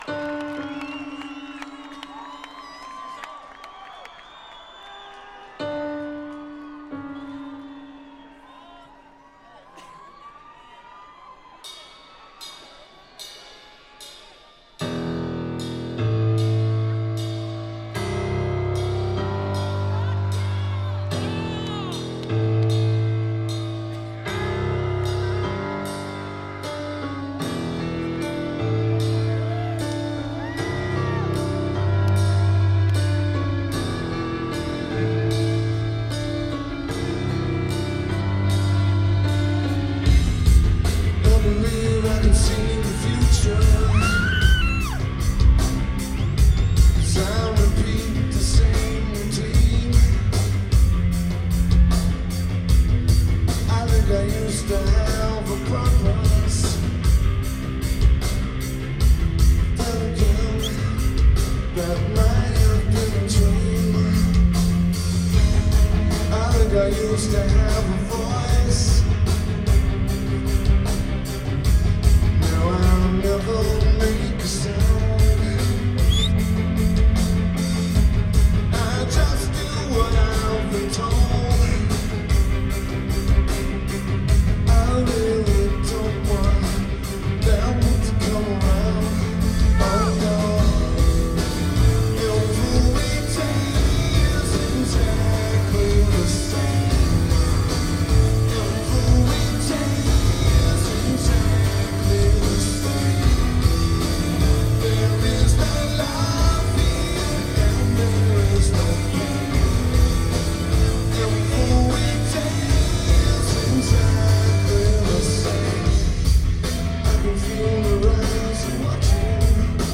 Aladdin Theatre